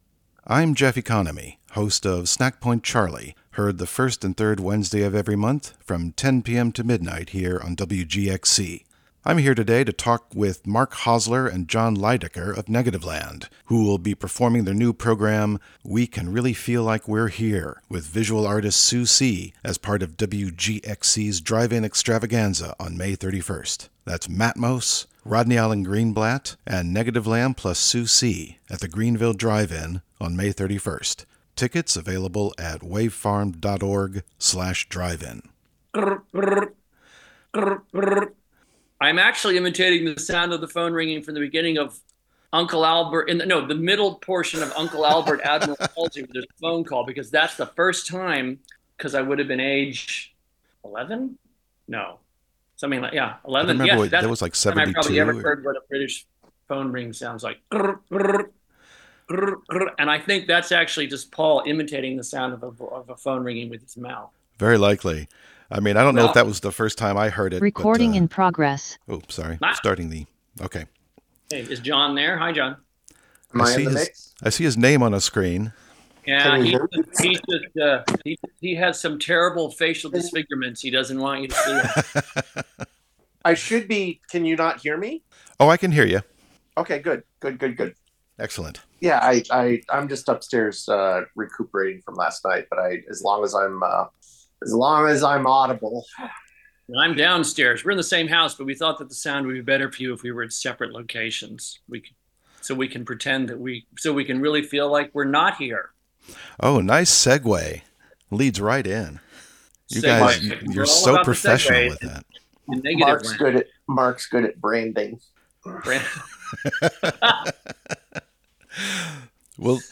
Interview with Negativland (Audio)